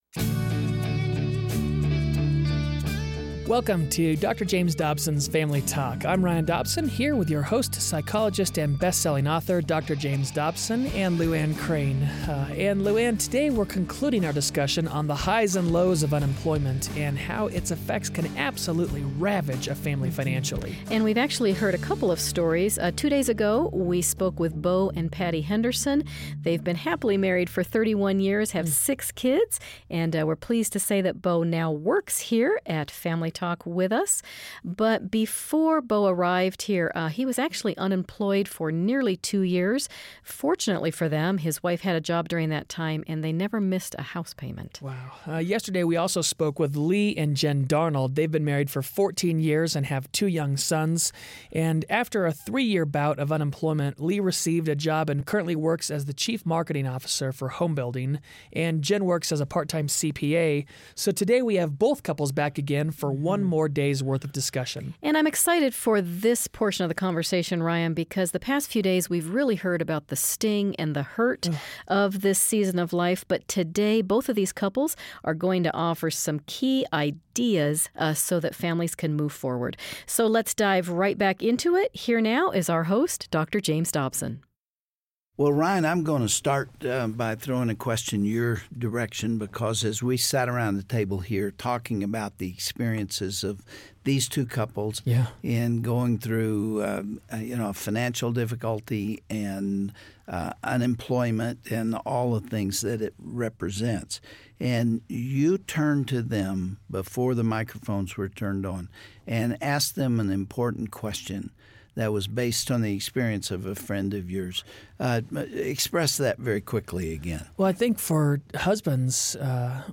Dr. Dobson is joined in the studio today by two couples who share their own story of adversity. They offer advice regarding how families can become more cohesive during a job transition, as well as how to be helpful to others in the same situation.